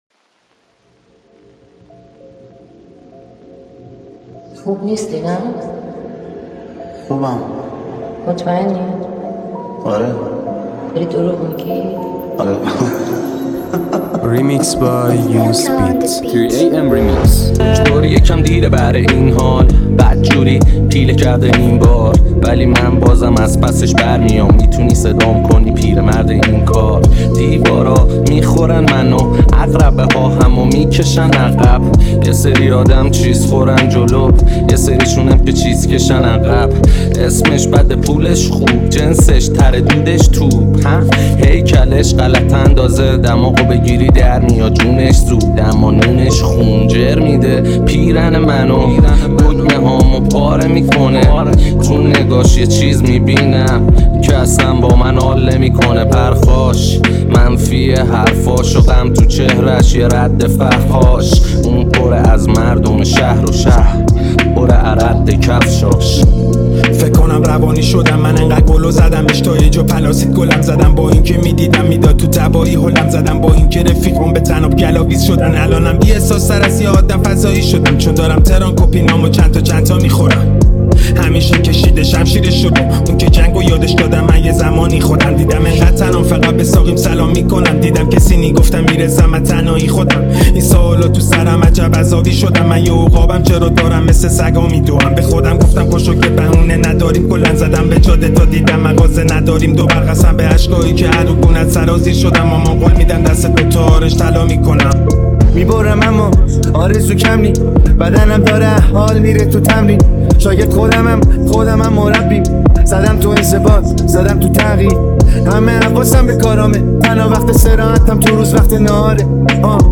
ریمیکس ترکیبی رپ